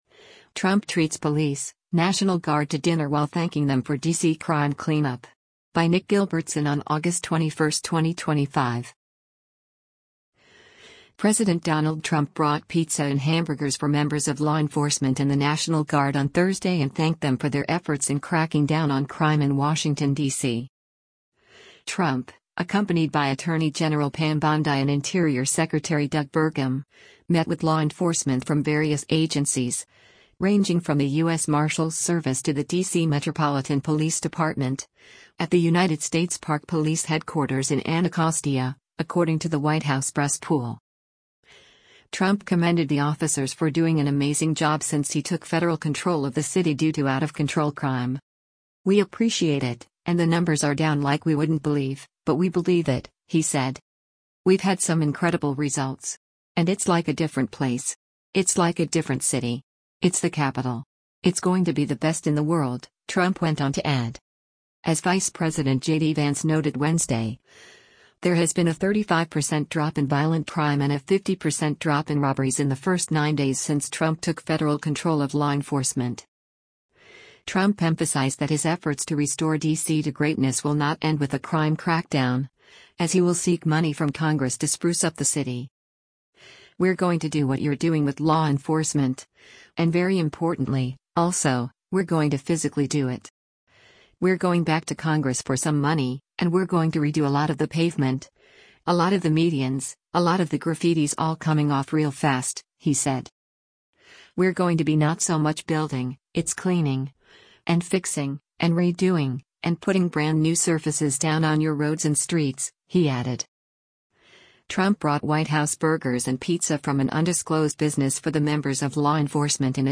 US President Donald Trump speaks while visiting federal troops at the US Park Police Anaco